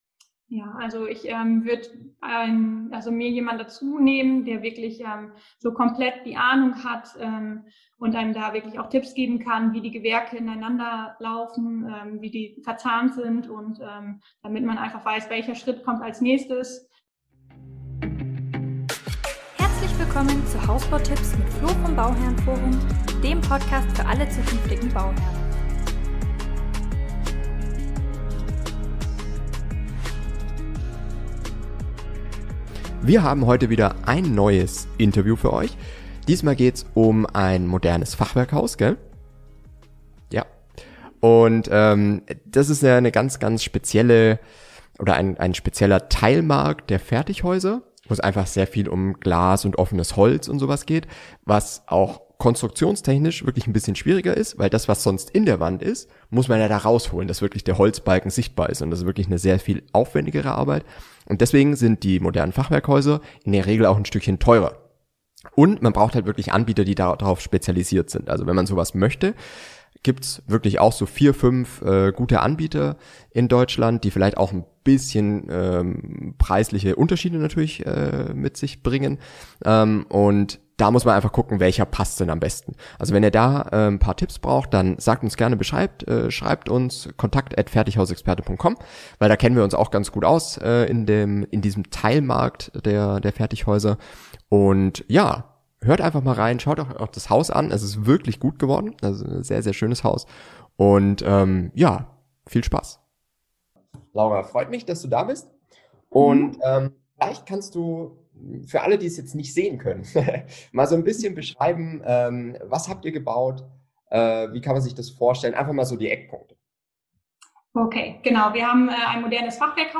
Modernes Fachwerkhaus bauen - Interview